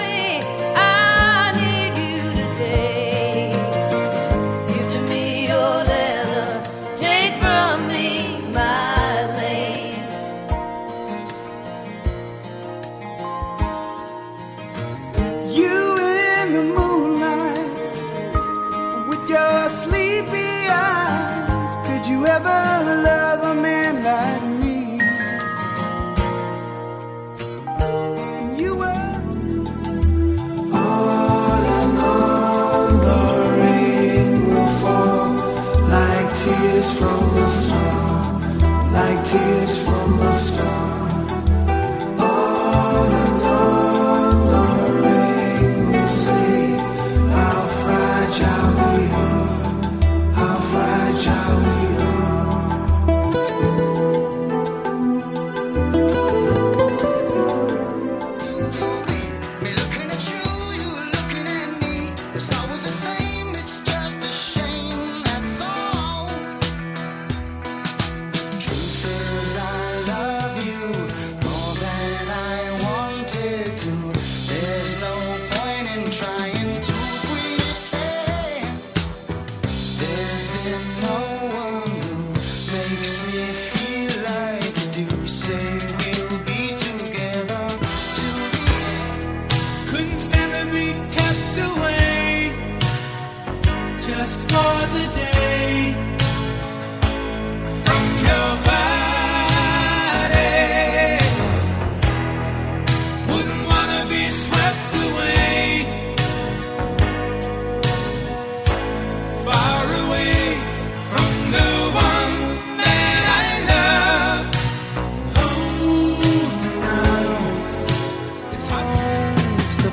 Smooth Rock
Best of Smooth Rock Songs